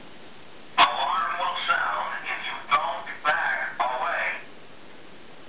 However, he also knows a couple of phrases: (click to hear Wolfgang speak)
First warning